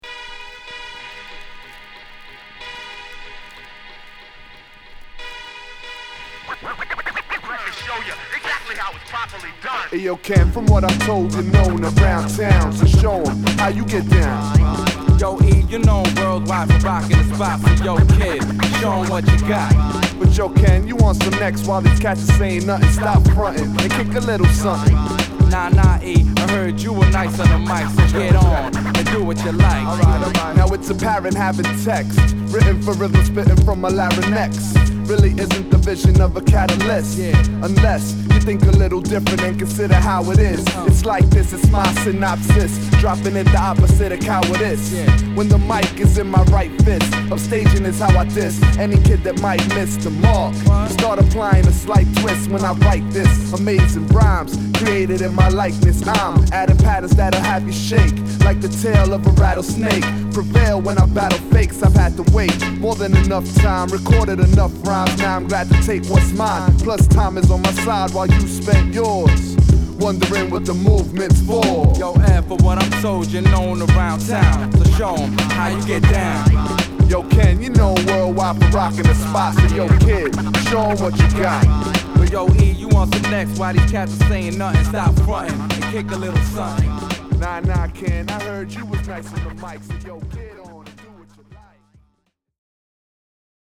ホーム HIP HOP UNDERGROUND 12' & LP D